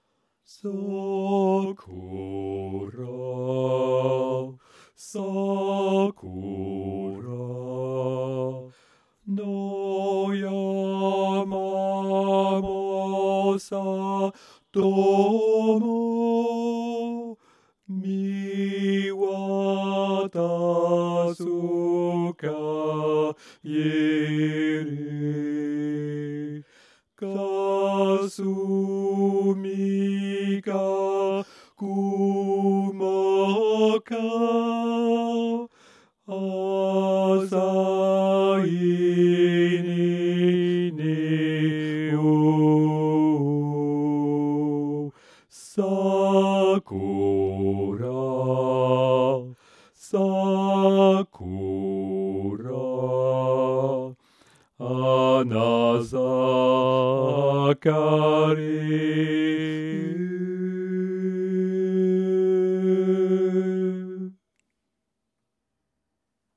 Basse
SakuraBasse.mp3